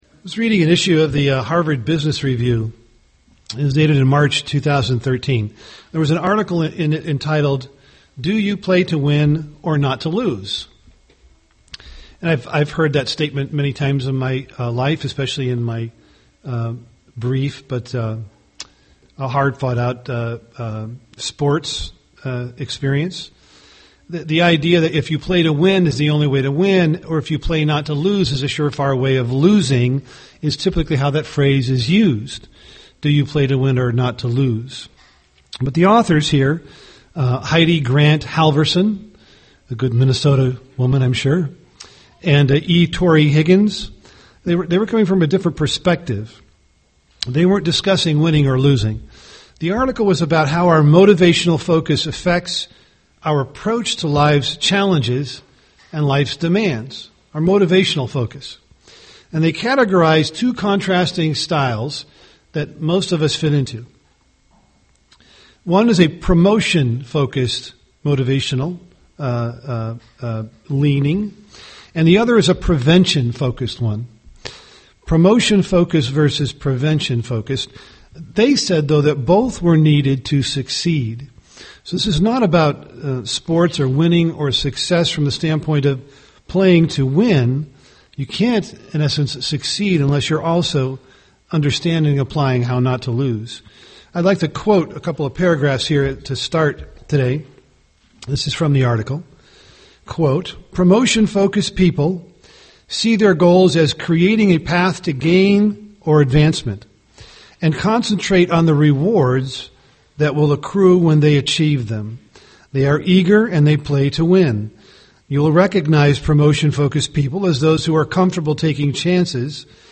UCG Sermon spirit of God Word of God growth Studying the bible?